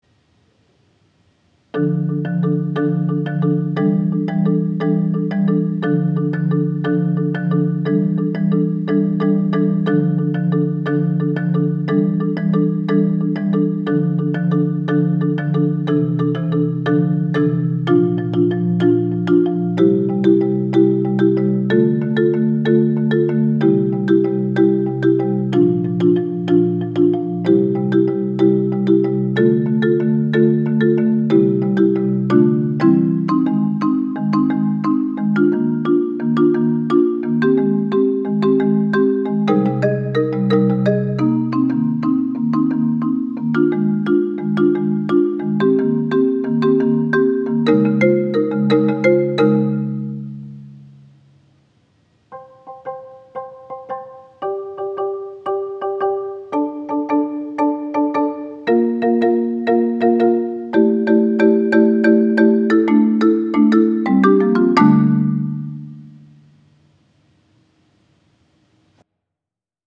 4-mallets marimba solo